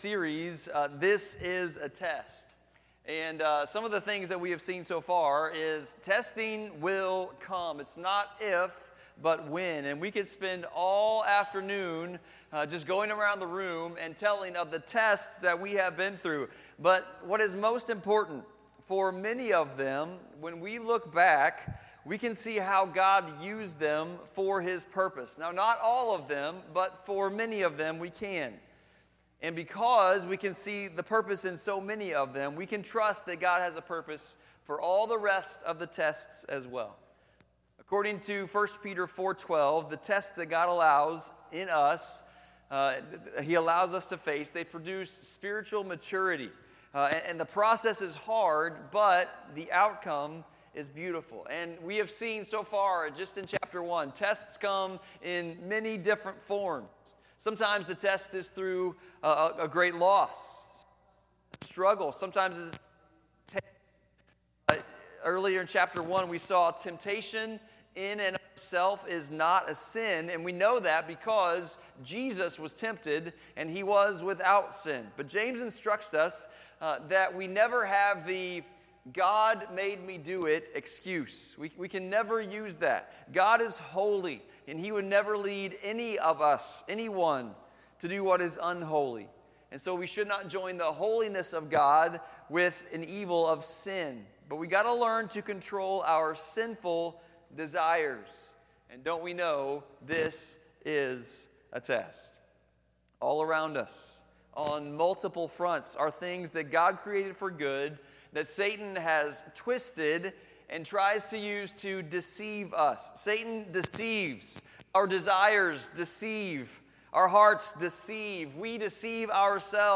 Sermons by FBC Potosi